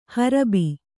♪ harabi